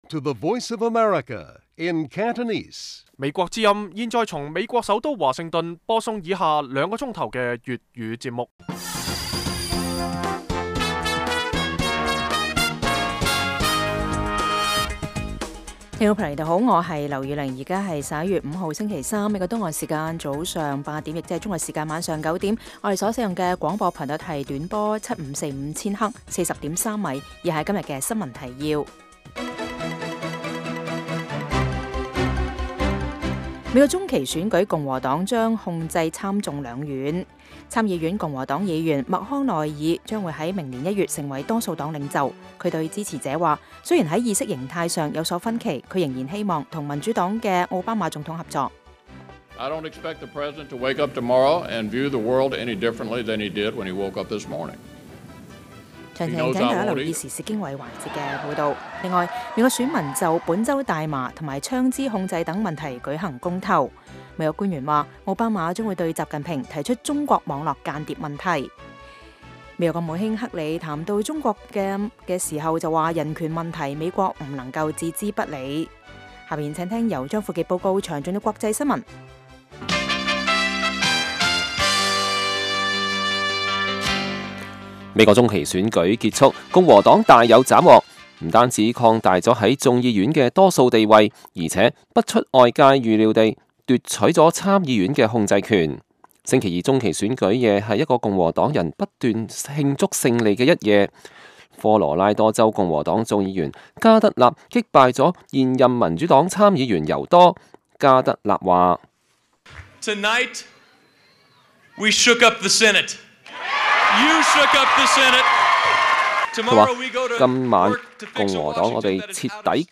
每晚 9點至10點 (1300-1400 UTC)粵語廣播，內容包括簡要新聞、記者報導和簡短專題。